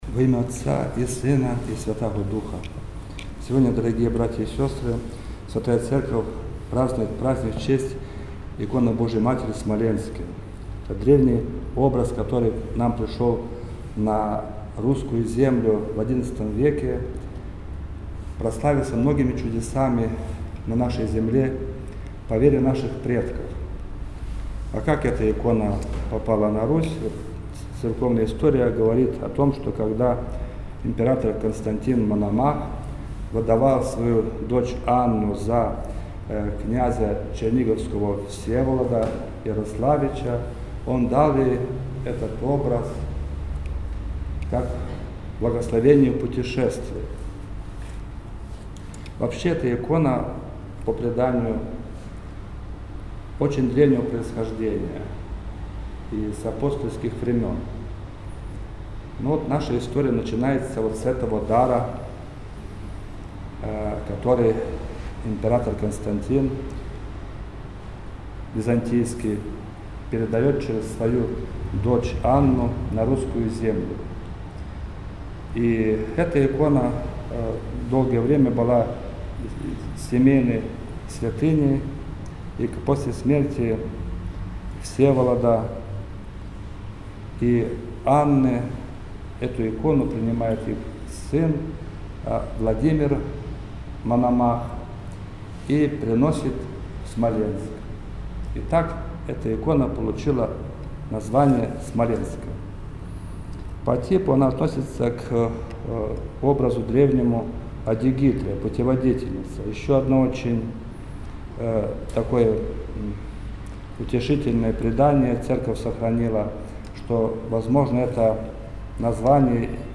10-авг-Проповедь-1.mp3